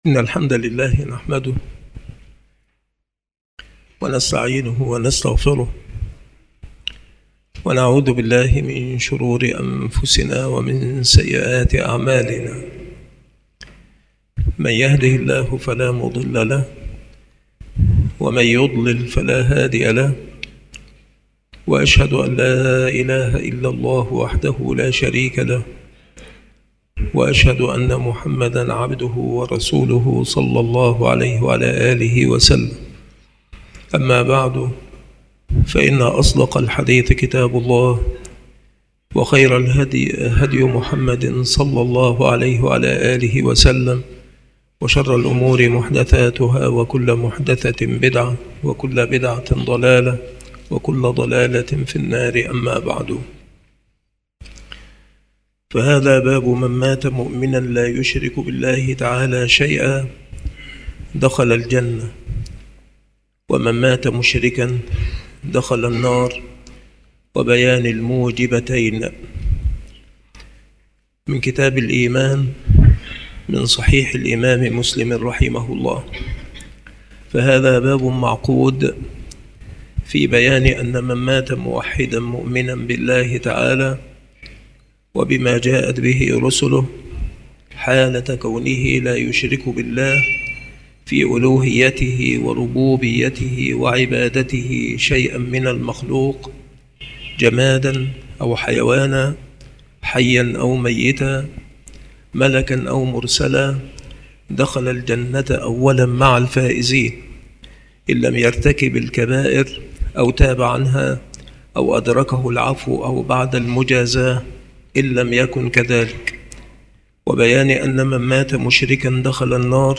التصنيف شروح الحديث
مكان إلقاء هذه المحاضرة بالمسجد الشرقي بسبك الأحد - أشمون - محافظة المنوفية - مصر